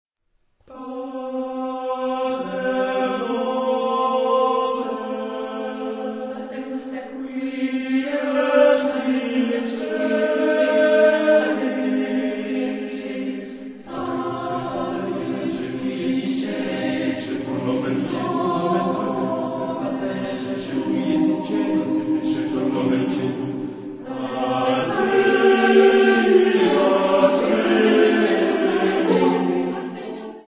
Género/Estilo/Forma: Sagrado ; Plegaria
Tipo de formación coral: SSAATTBB  (8 voces Coro mixto )
Tonalidad : atonal